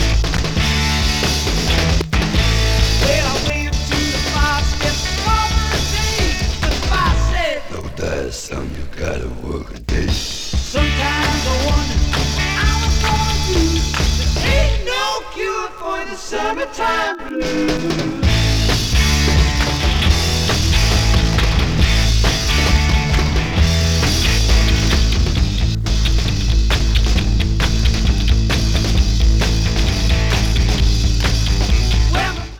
Mono) (UK acetate version - speed corrected